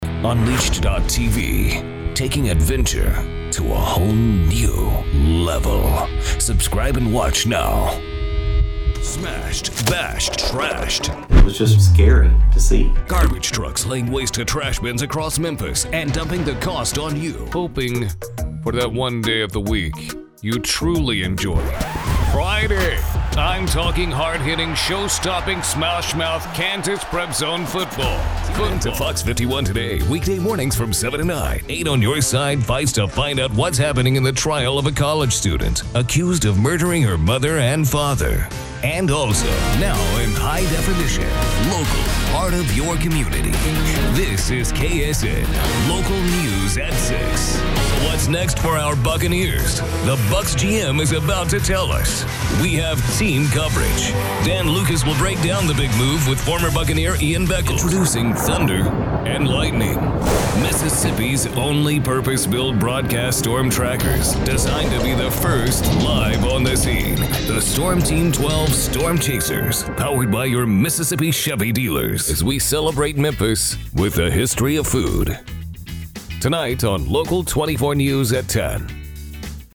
Since 2001, I’ve worked nationally and internationally as a voice over talent, delivering broadcast-ready reads for TV, radio, automotive, and documentary projects from a professional studio.
Middle Aged